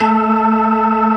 54_20_organ-A.wav